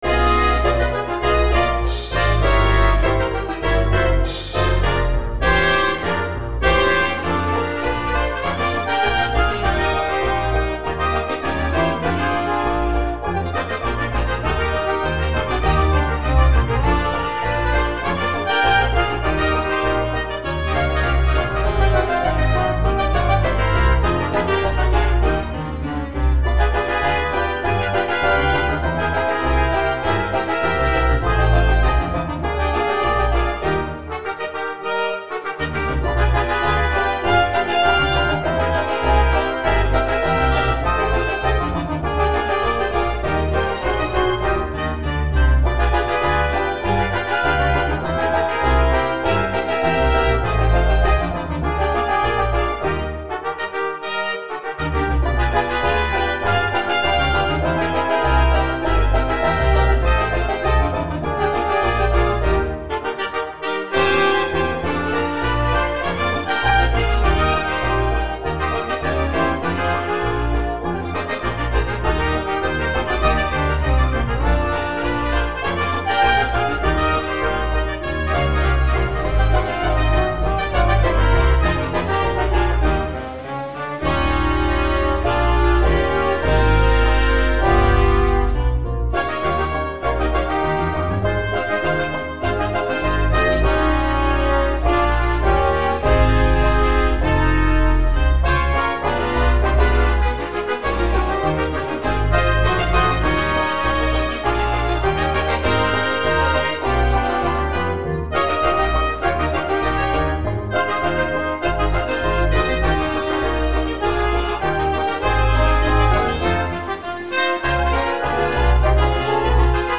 Early jazz orchestra